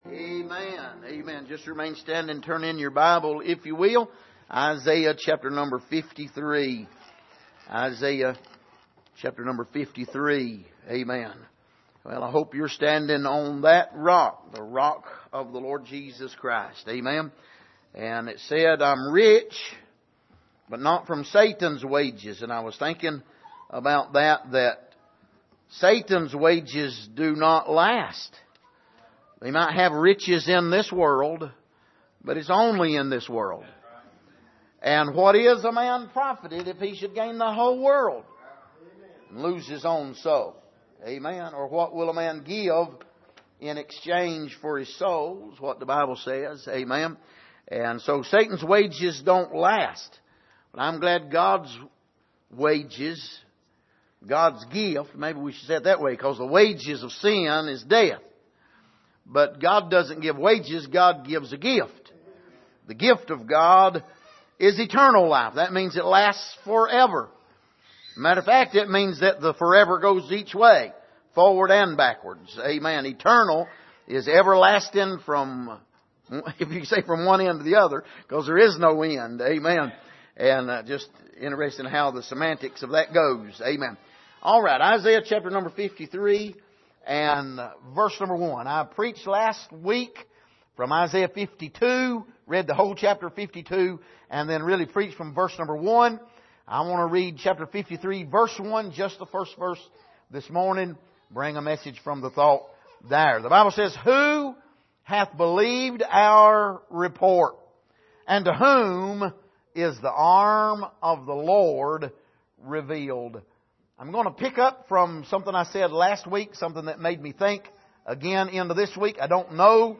Passage: Isaiah 52:1-15 Service: Sunday Morning